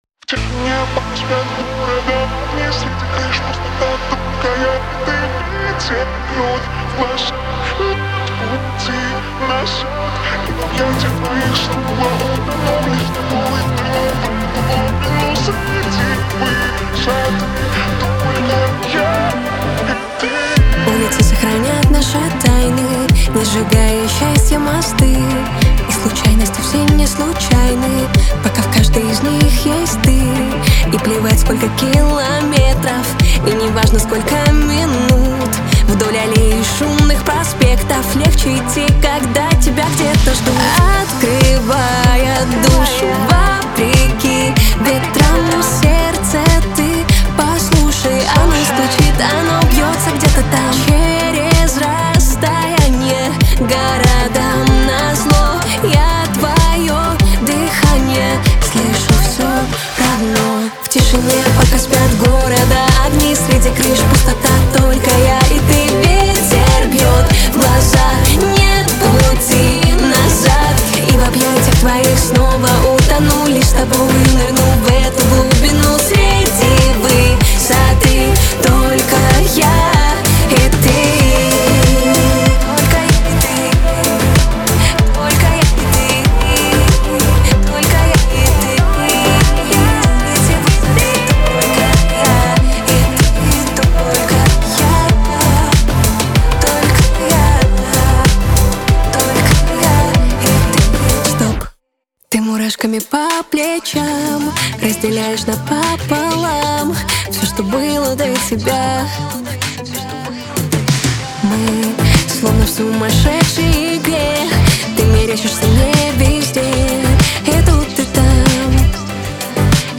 Русская Поп-Музыка